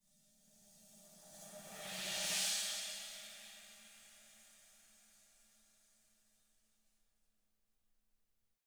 Index of /90_sSampleCDs/ILIO - Double Platinum Drums 1/CD4/Partition I/RIDE SWELLD